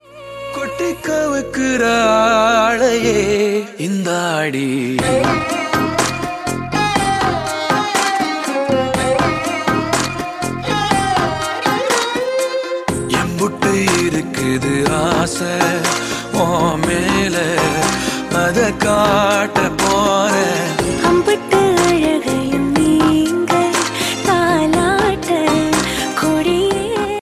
best flute ringtone download | dance song ringtone